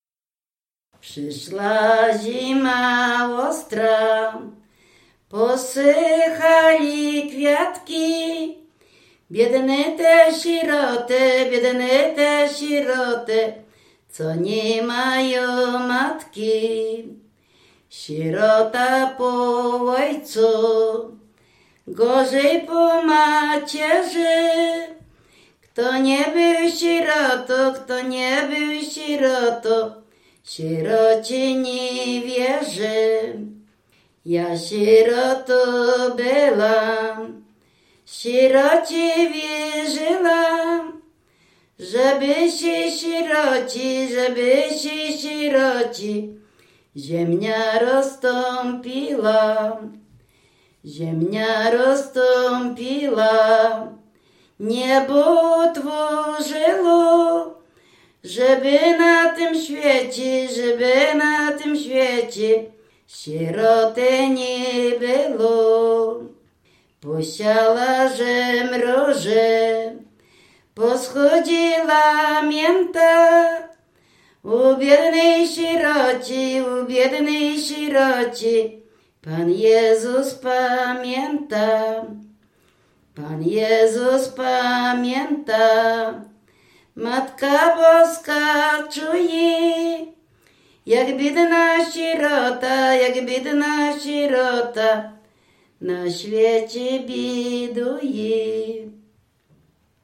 województwo dolnośląskie, powiat lwówecki, gmina Mirsk, wieś Mroczkowice
W wymowie Ł wymawiane jako przedniojęzykowo-zębowe;
e (é) w końcu wyrazu zachowało jego dawną realizację jako i(y)
liryczne ballady sieroce